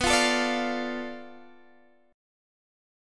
Bm7b5 Chord (page 2)
Listen to Bm7b5 strummed